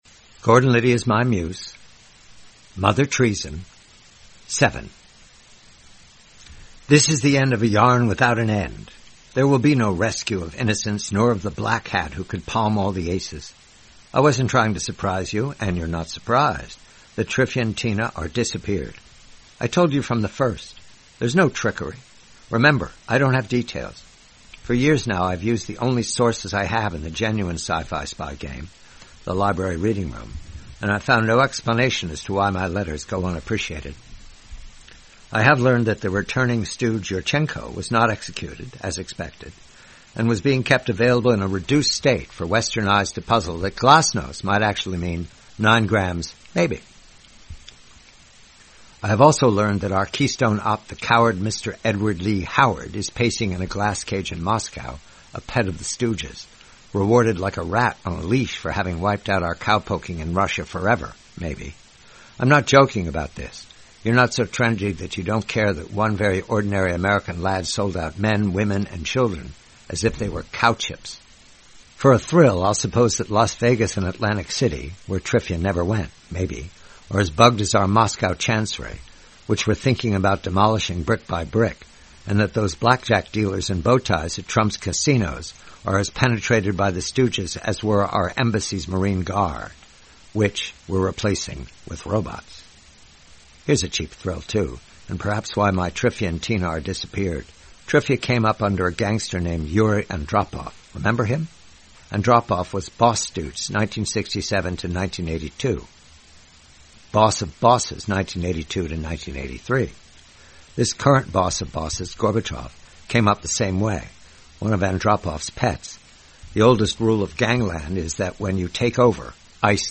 Read by John Batchelor.